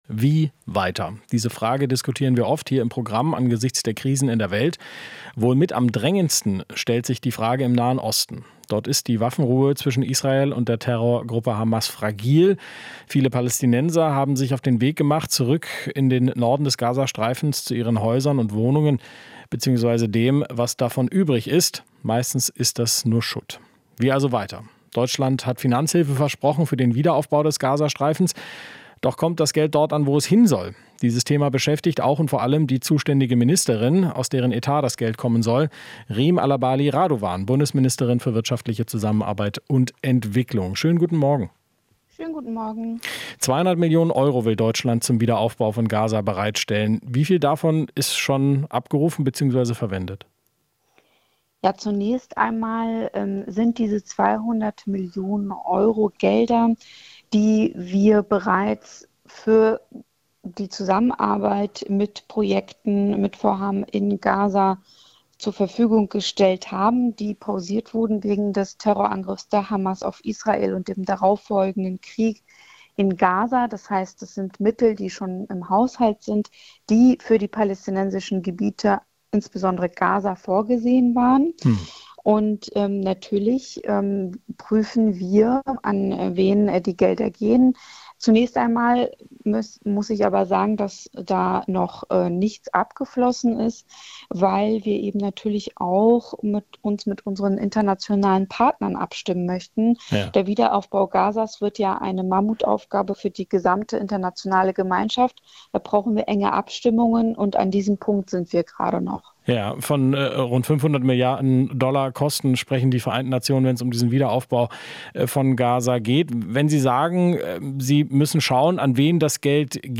Entwicklungsministerin Reem Alabali Radovan (SPD)